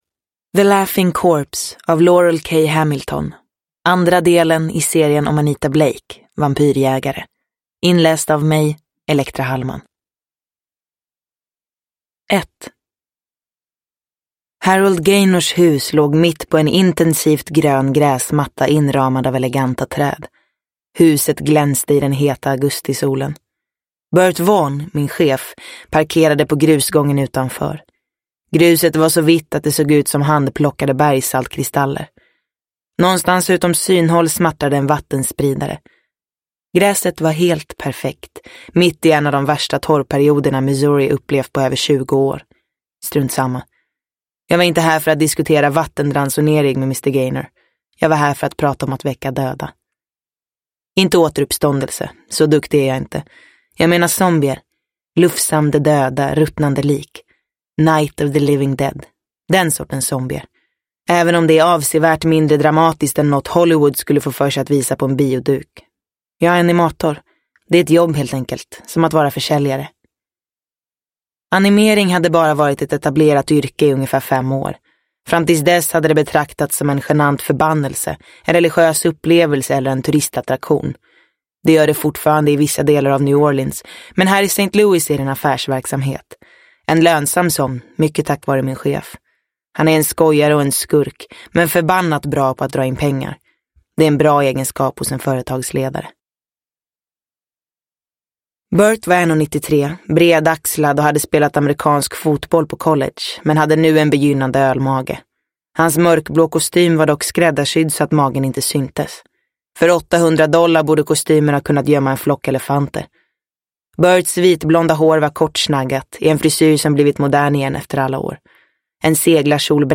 The Laughing Corpse – Ljudbok – Laddas ner